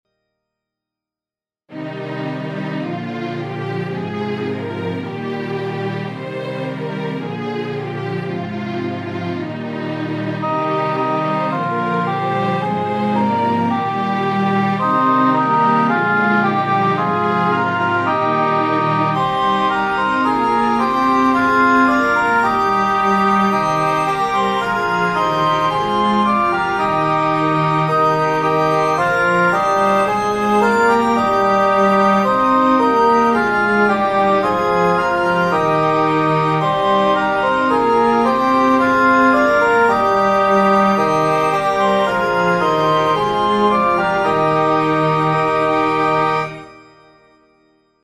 Keyboard, Kanon, 4-stimmig Es-Dur